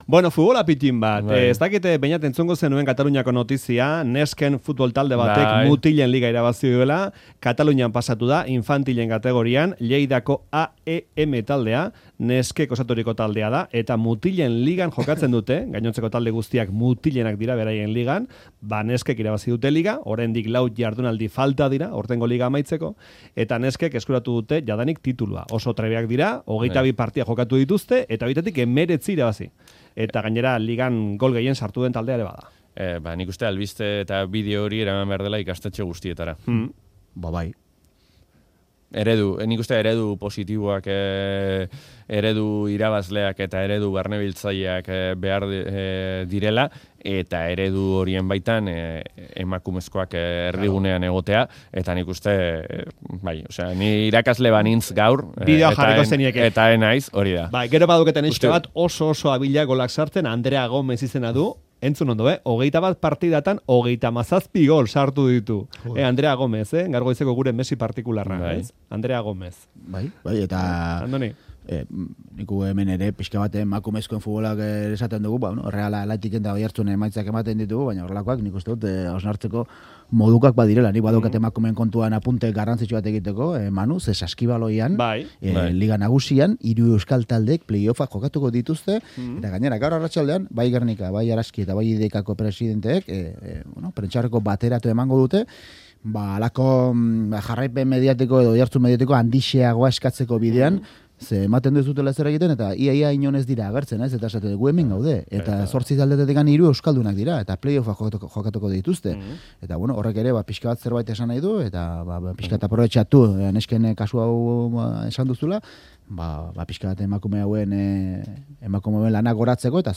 Kirol solasaldia | emakumezkoen futbola | Katalunia | Euskadi Irratia